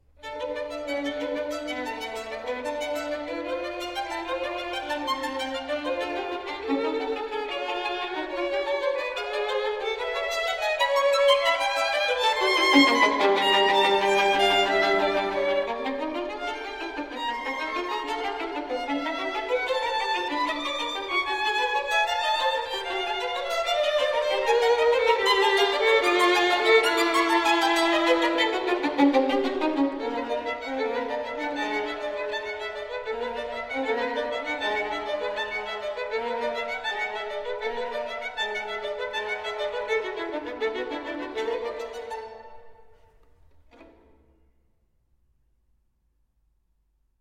Violine
Pentatonisch II